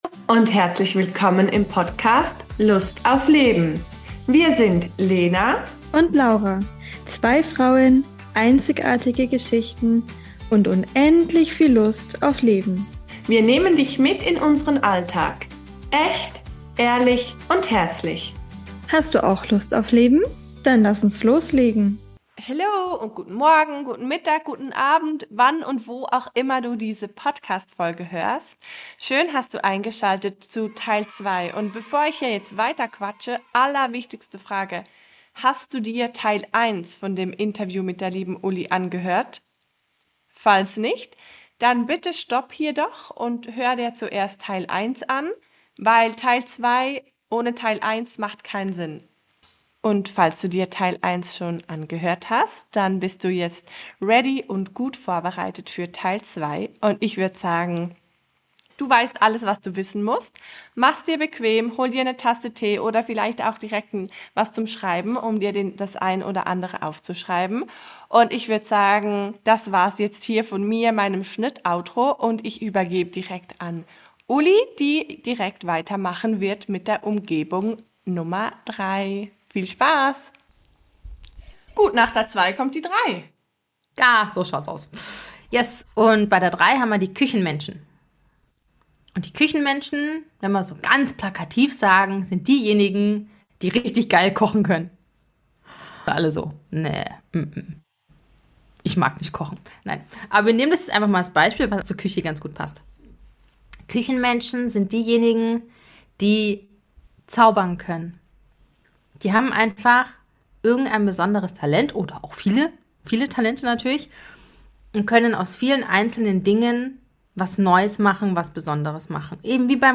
#27 Human Design Umgebungen – der Ort, an dem du dich wirklich wohlfühlst | Interview